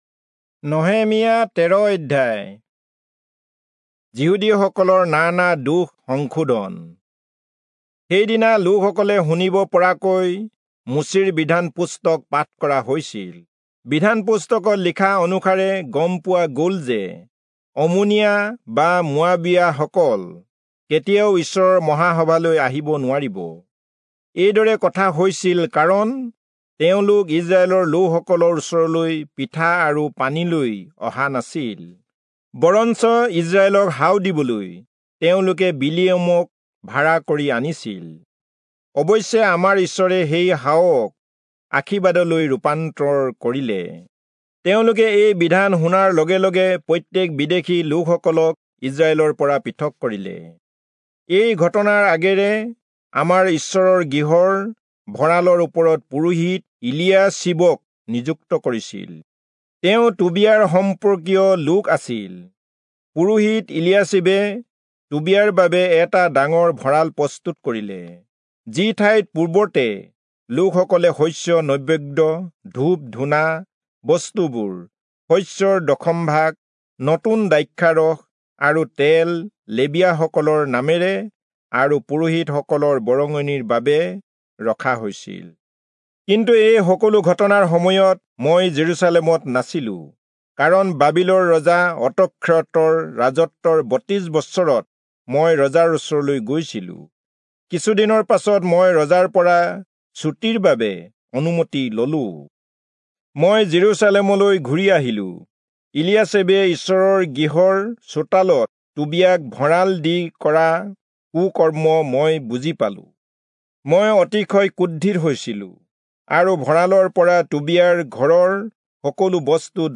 Assamese Audio Bible - Nehemiah 2 in Web bible version